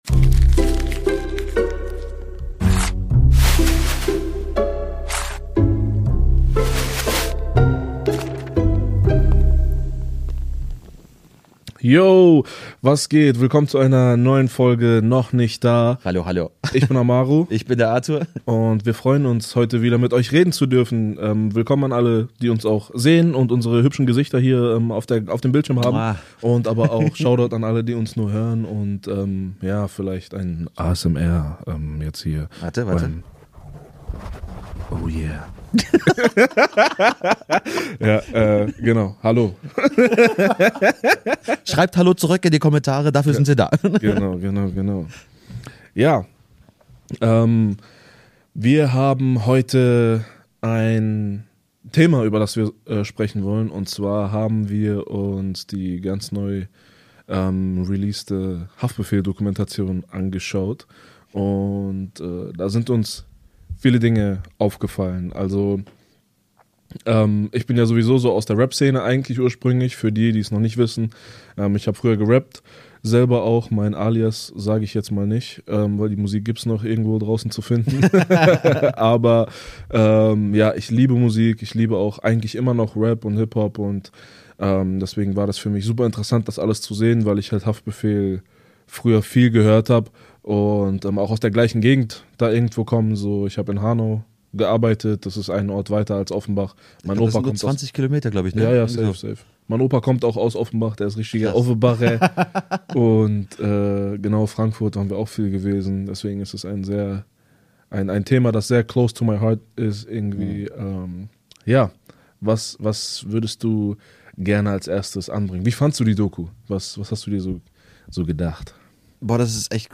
Zwei Kumpels, ehrliche Gespräche, echte Fragen – und eine Menge Inspiration fürs Leben.